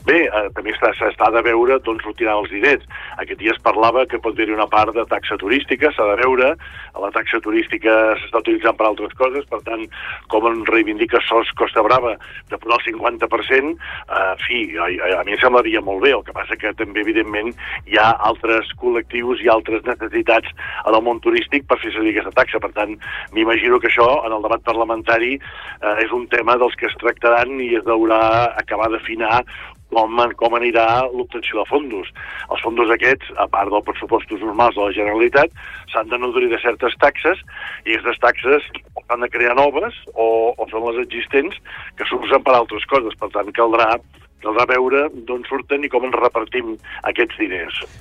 Entrevistes Supermatí
Així ho ha confirmat en una entrevista al Supermatí el secretari de transició ecològica de la Generalitat, Jordi Sargatal, qui assegura que tots els grups parlamentaris estan alineats per aprovar el text en els pròxims mesos.